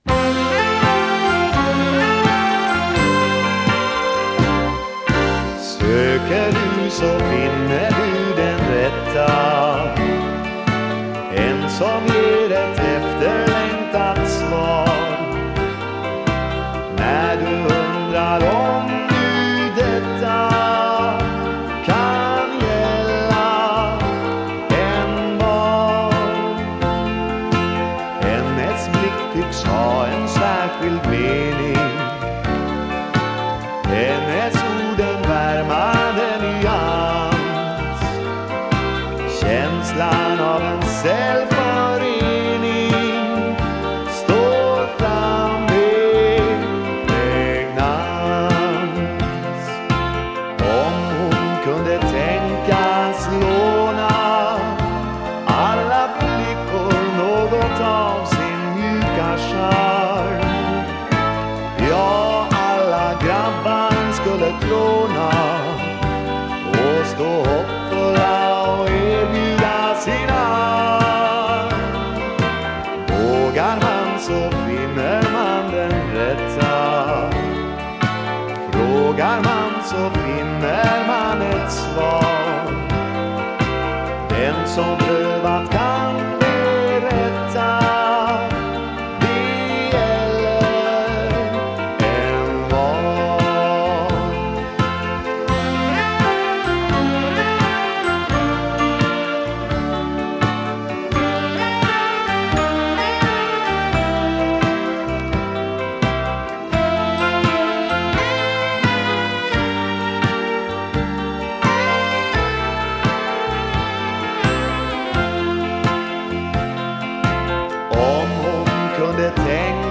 Vocals, Piano, Accordion
Bass
Vocals, Drums, Percussion
Vocals, Guitars
Sax